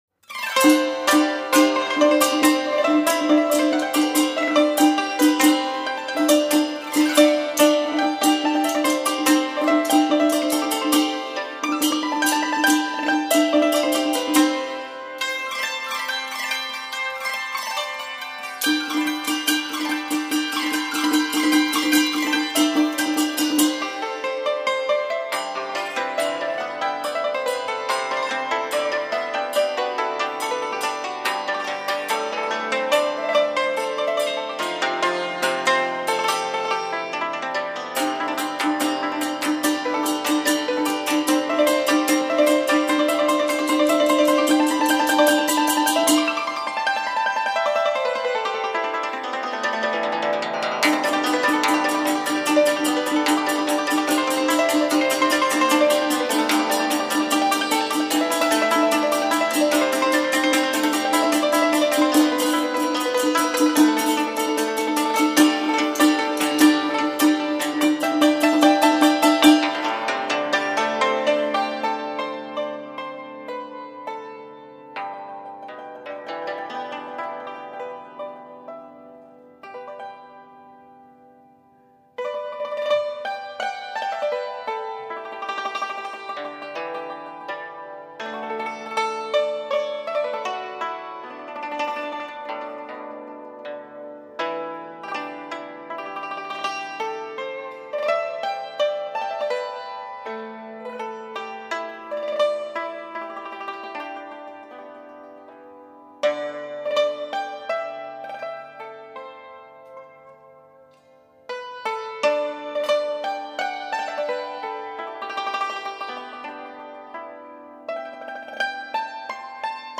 日本Memory-Tech株式会社专利母盘制作技术，高品位再现“音乐的空气感”。
扬琴